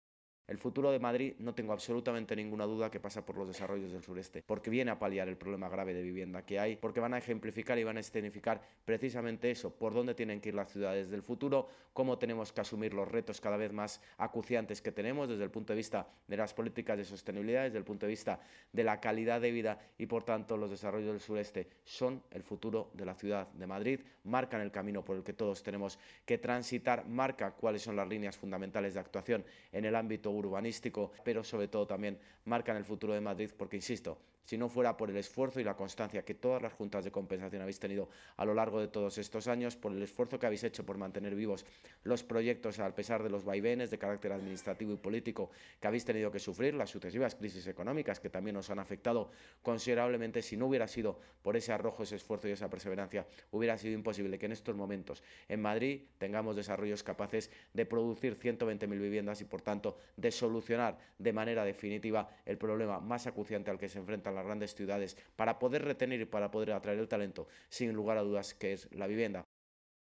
El alcalde de Madrid, José Luis Martínez-Almeida, acompañado de la delegada de Obras y Equipamientos, Paloma García Romero; el concejal delegado de Vivienda, Álvaro González, y el concejal de Vicálvaro, Martín Casariego, ha mantenido esta mañana un encuentro con representantes de las juntas de compensación de Los Berrocales, El Cañaveral, Los Cerros, Los Ahijones y Valdecarros, los llamados desarrollos del sureste.